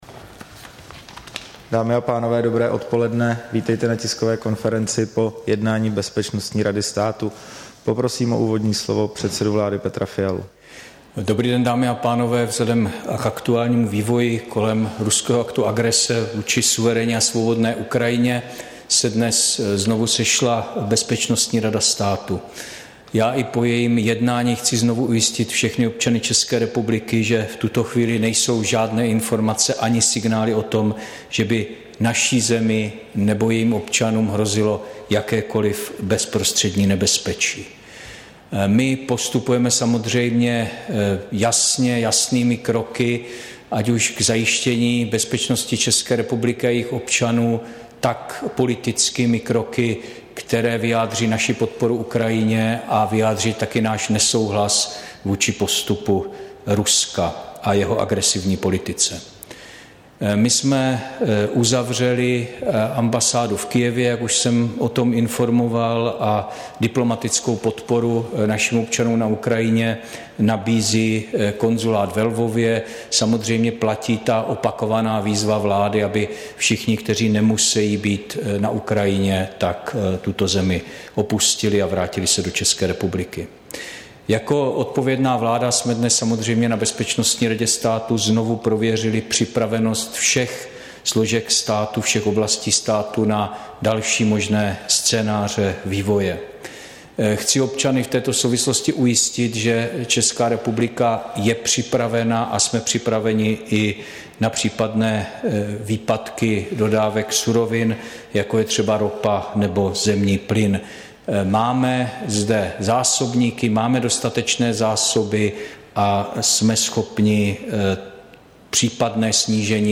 Tisková konference po mimořádném jednání Bezpečnostní rady státu, 24. února 2022